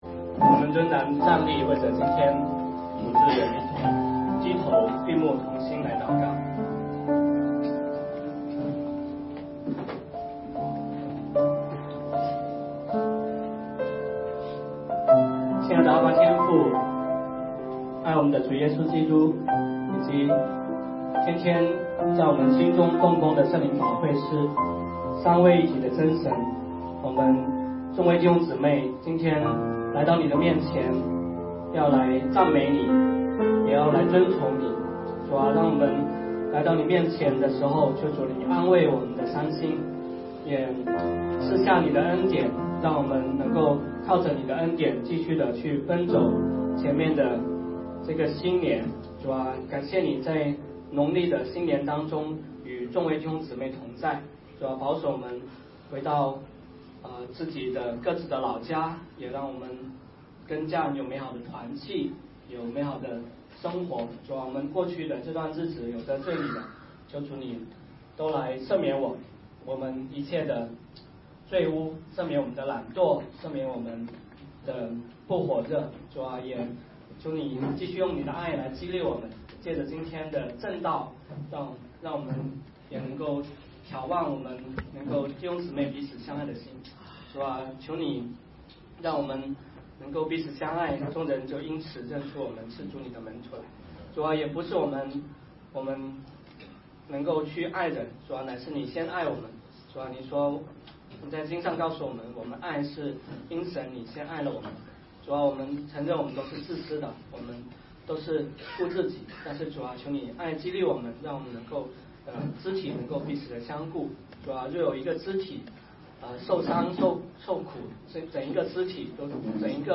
罗马书第19讲 2016年2月21日 下午9:57 作者：admin 分类： 罗马书圣经讲道 阅读(4.29K